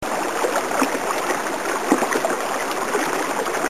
smallwaterfall.mp3